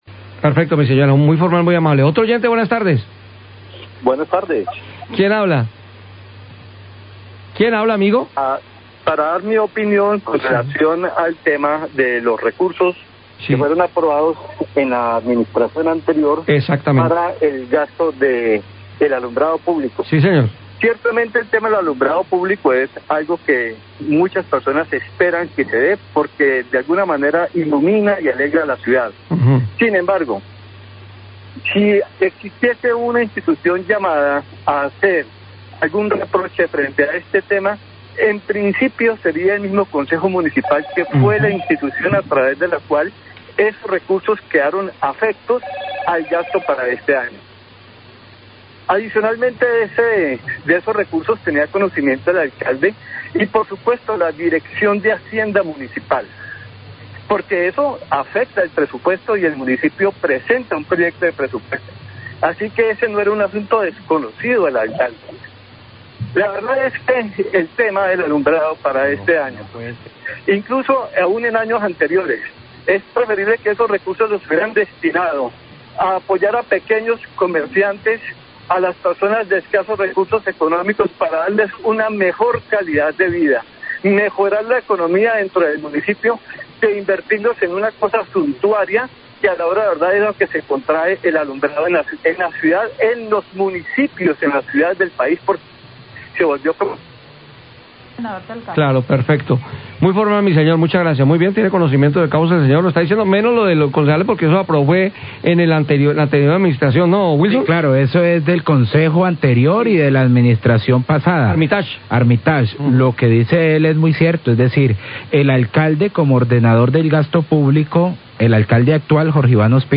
Oyente critica costo del alumbrado navideño,